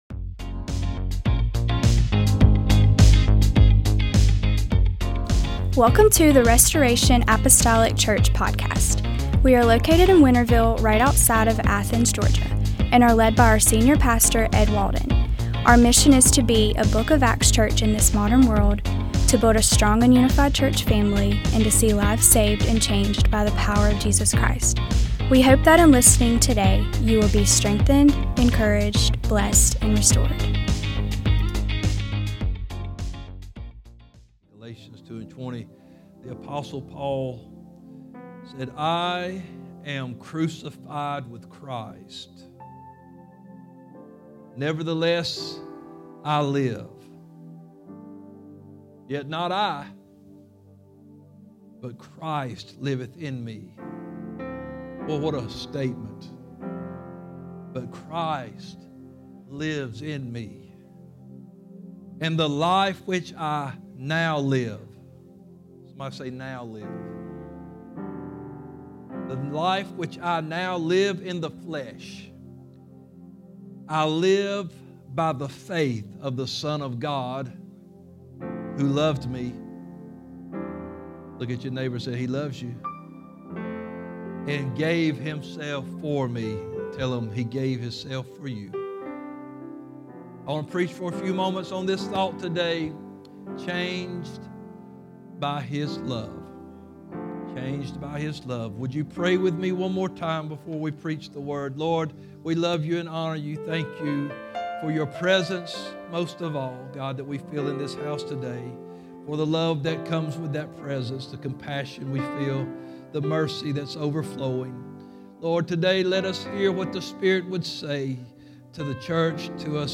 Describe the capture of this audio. Restoration Apostolic Church Changed By His Love Feb 09 2025 | 00:52:08 1x 00:00 / 00:52:08 Subscribe Share Apple Podcasts Spotify Overcast RSS Feed Share Link Embed